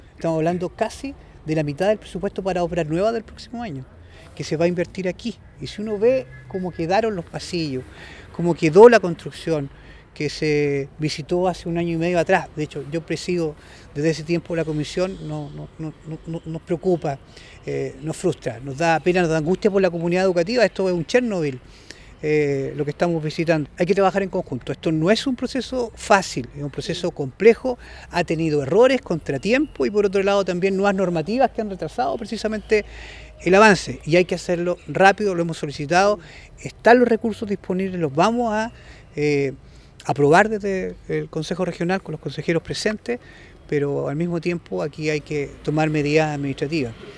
El Consejero Regional añadió que desde la última visita técnica esta situación empeoró, por lo que se debe trabajar en conjunto con todos los entes públicos para avanzar y tomar las medidas administrativas pertinentes.